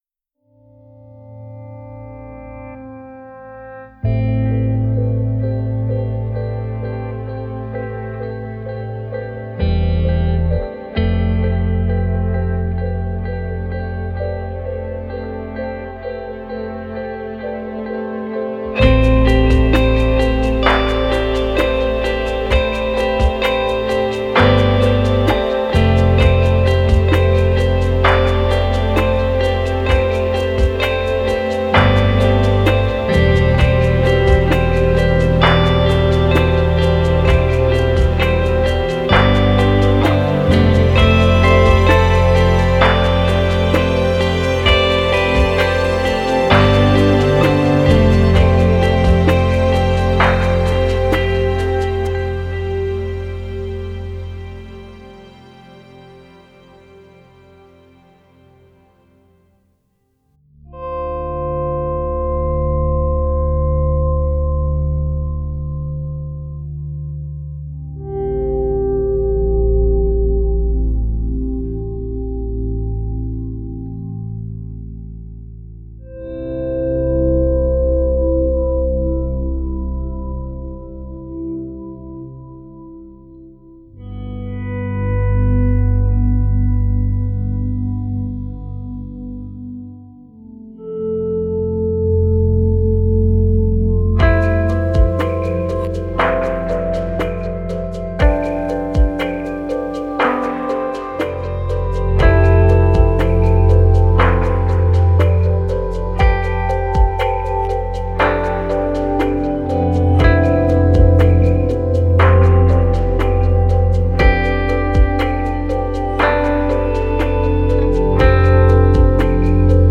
Genre: Score.